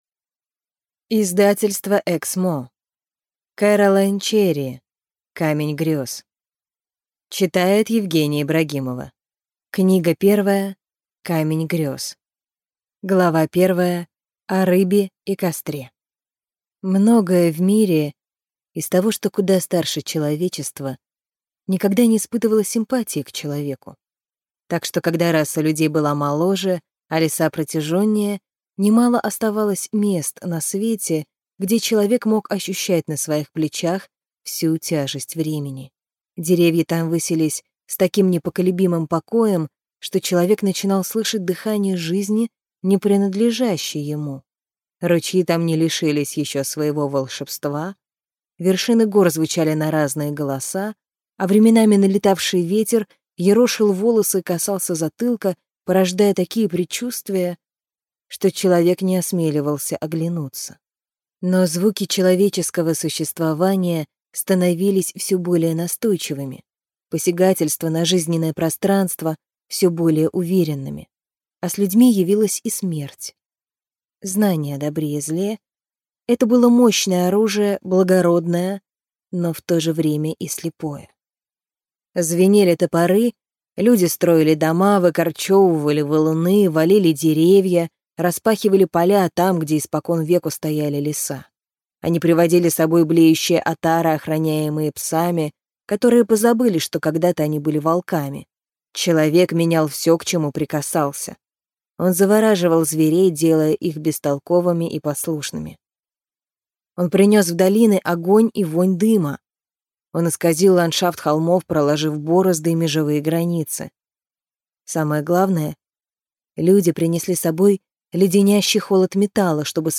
Аудиокнига Камень Грез | Библиотека аудиокниг
Прослушать и бесплатно скачать фрагмент аудиокниги